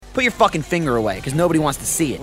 rage gaming angry anger